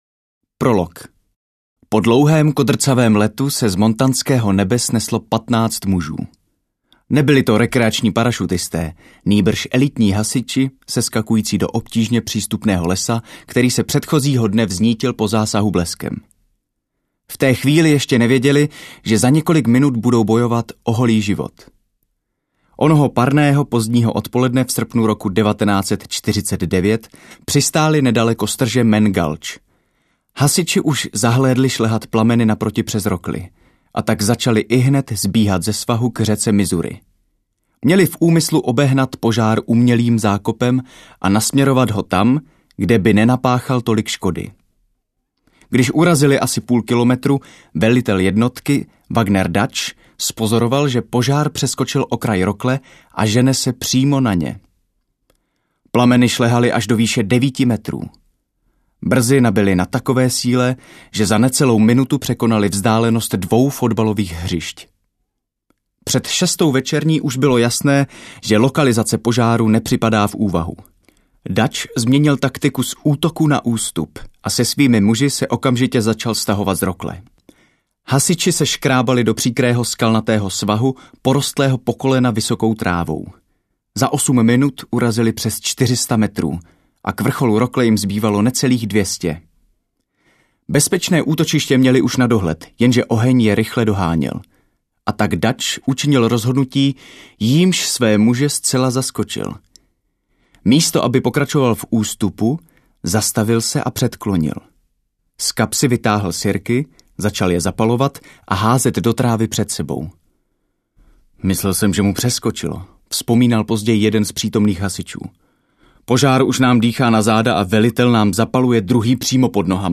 Audiokniha Ještě to promysli - Adam Grant | ProgresGuru